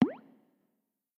Звуки лопающихся пузырьков
Звук лопающегося пузыря в смс